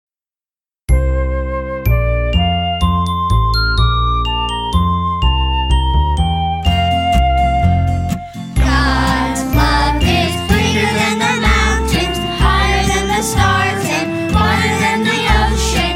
Praise Song for Children